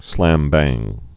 (slămbăng)